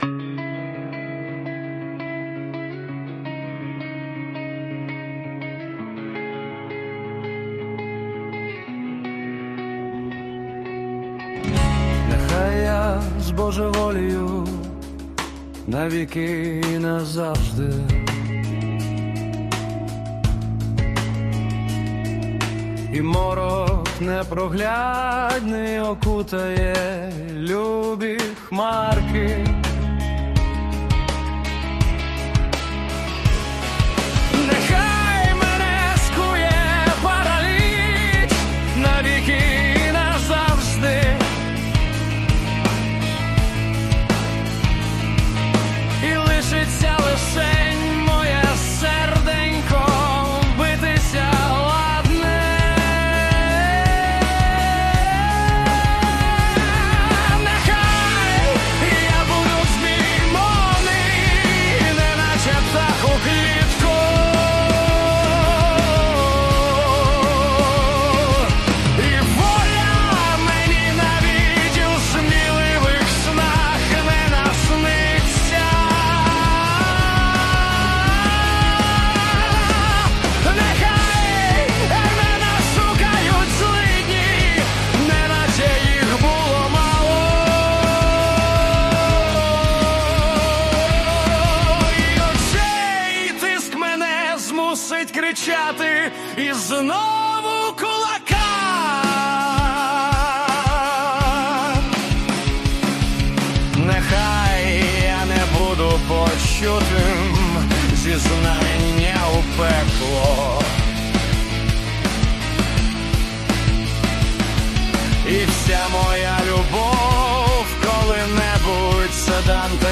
Музыка и исполнение принадлежит ИИ.
ТИП: Пісня
СТИЛЬОВІ ЖАНРИ: Драматичний